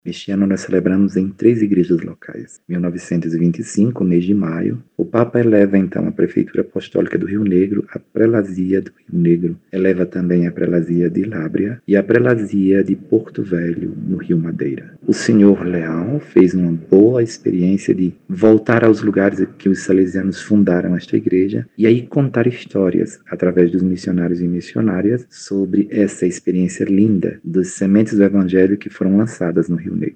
Segundo o bispo da Diocese de São Gabriel da Cachoeira, Dom Vanthuy Neto, a produção apresenta a memória missionária e o legado dos primeiros anunciadores do Evangelho.
Sonora-1-–-Dom-Vanthuy-Neto.mp3